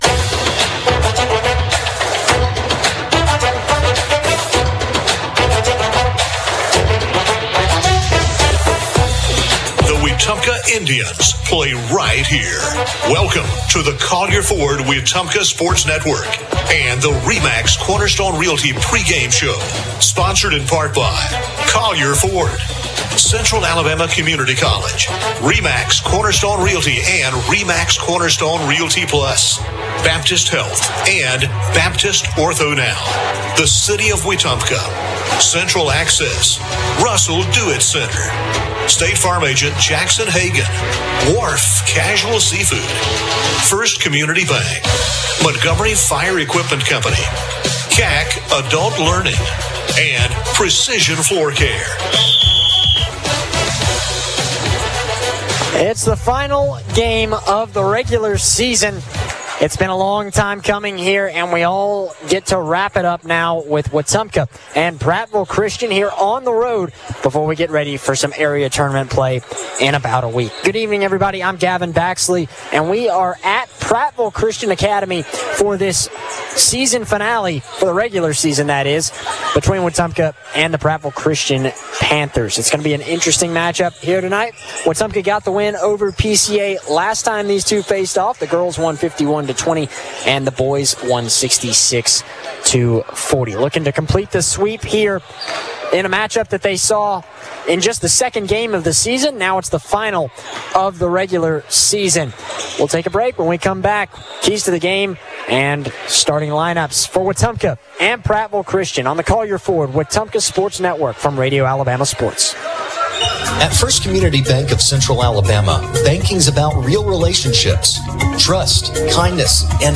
(Girls Basketball) Wetumpka vs. Prattville Christian